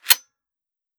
fps_project_1/30-30 Lever Action Rifle - Loading 003.wav at 7df45fd0e007ca1c7c844e5bc93373d1c70a88a7